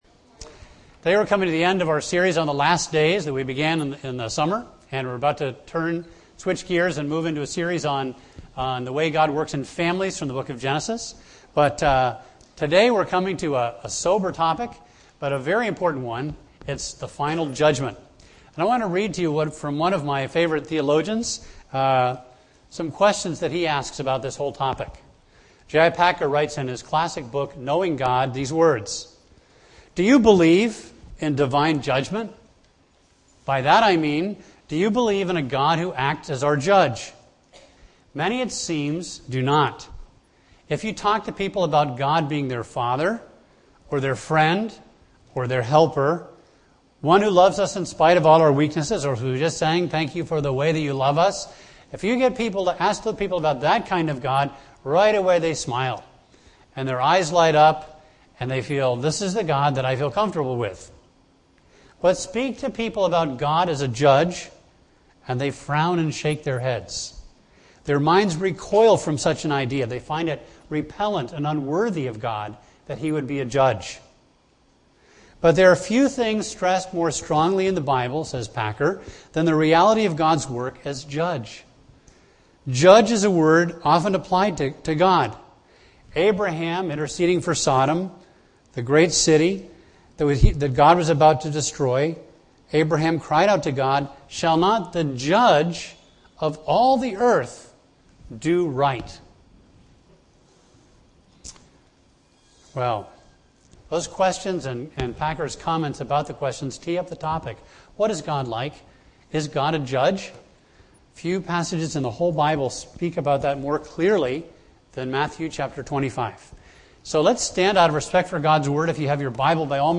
A message from the series "End Times."